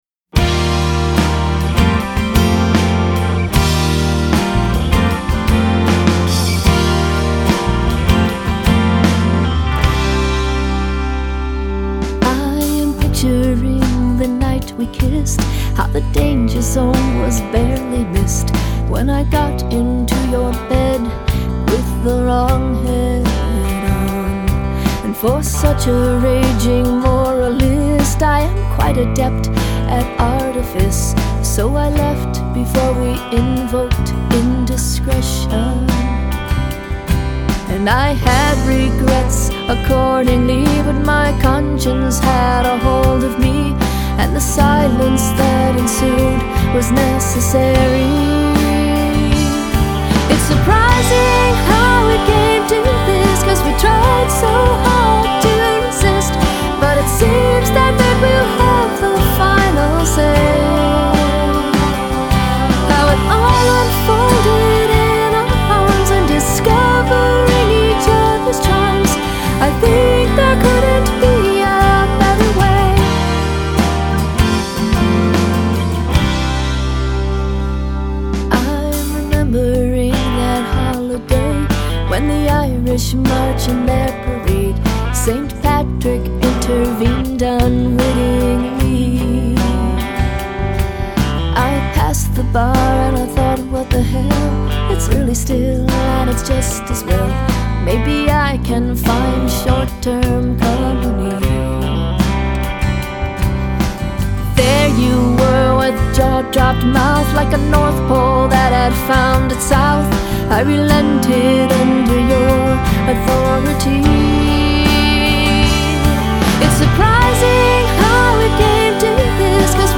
Uptempo Fmvx, Full band